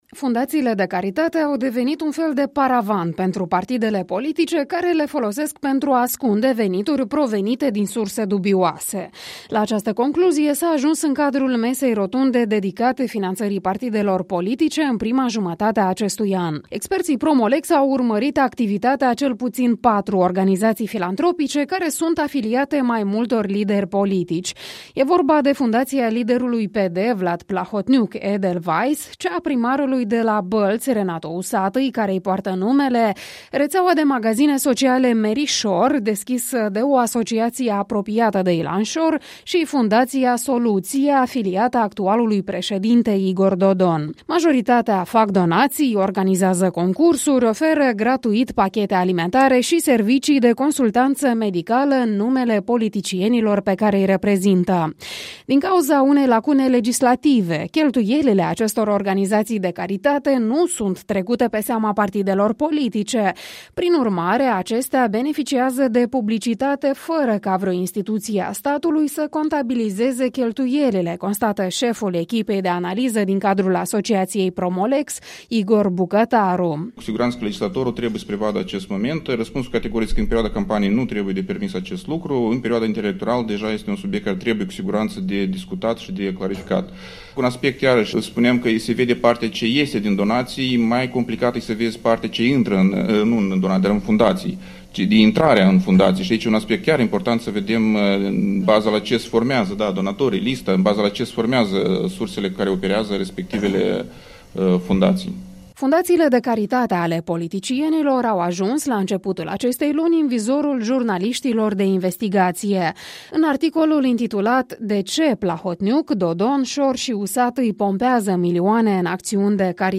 Masa rotundă organizată de Asociaţia Promo-Lex despre finanţarea partidelor politice